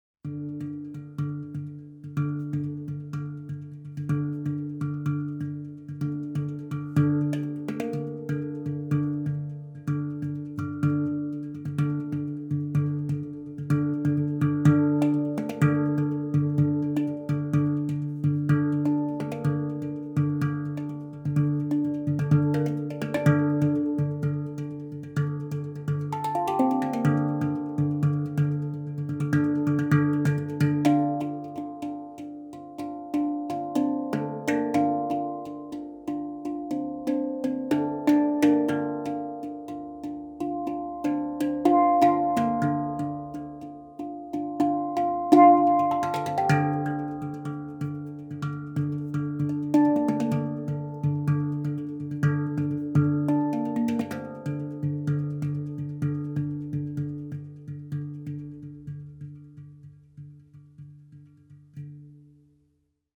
Durch das Anschlagen mit der Hand erzeugt die Harmonic Art Handpan klare und reine Töne. Der Klang ist angenehm, wohltuend und entspannend und kann vielseitig eingesetzt werden, egal ob in der Therapie oder bei Aufführungen. Jedes Instrument hat eine Grundstimmung und sieben Töne, somit kann der Spieler innerhalb einer Tonleiter spielen, sowohl als Solist als auch in der Gruppe.